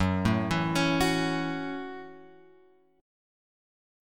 F# Minor 7th